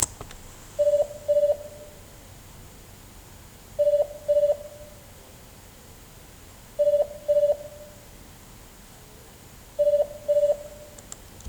【NAKAYO（ナカヨ）ST101A 内線着信音サンプル】
■内線着信音　F